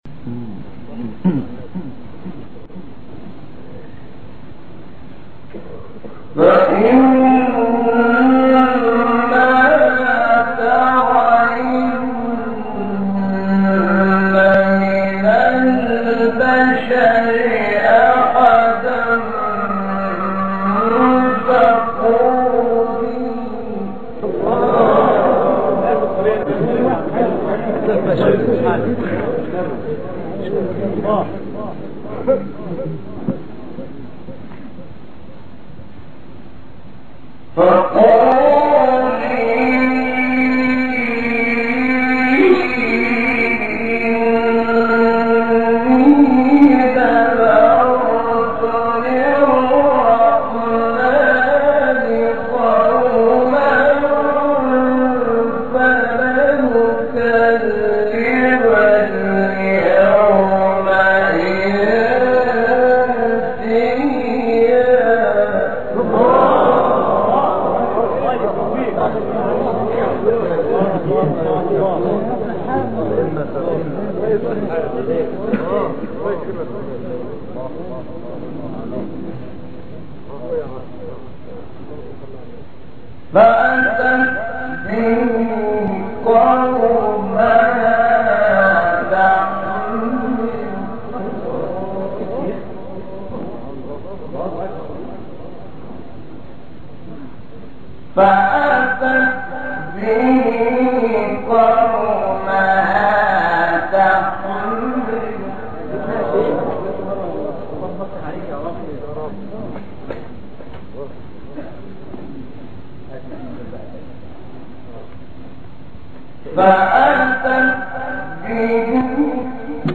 تلاوت زیبای سوره مریم شحات محمد انور | اجرا مصر | نغمات قرآن
مقام : بیات * صبا